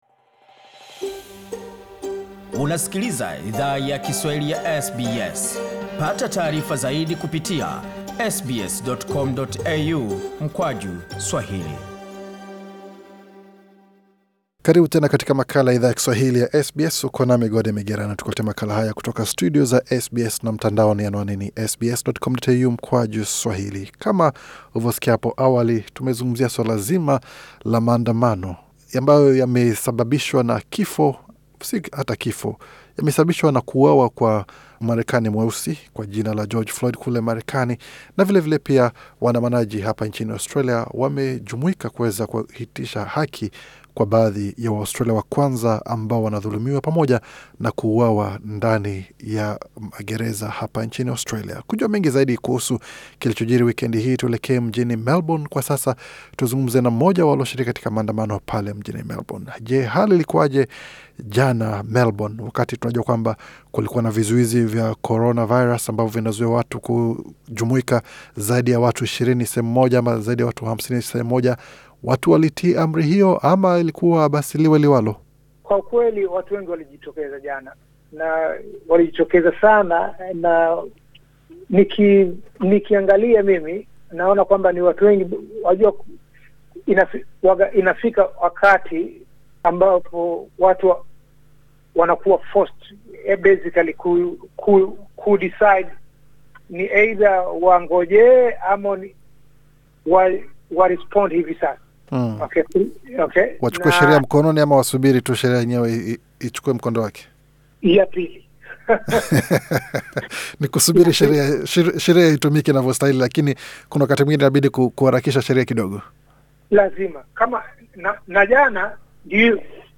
SBS Swahili ilizungumza na mwanaharakati aliye weka wazi yaliyo jiri katika maandamano hayo mjini Melbourne, Victoria.